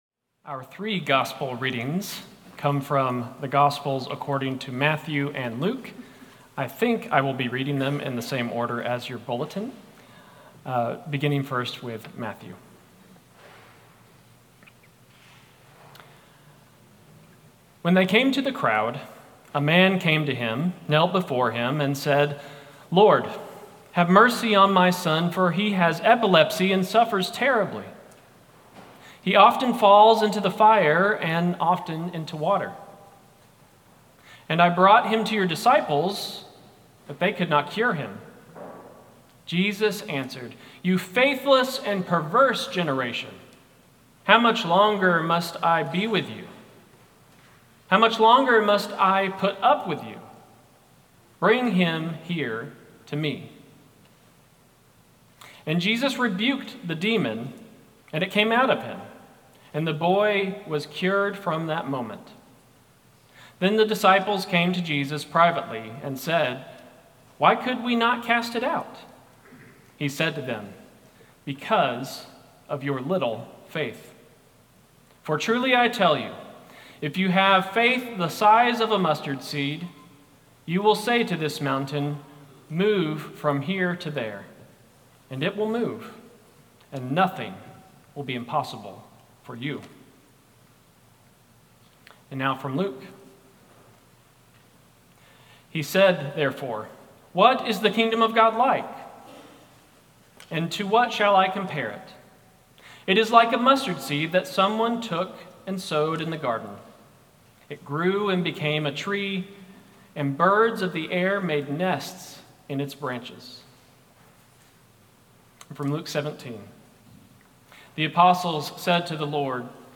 Sermon+11-9-25.mp3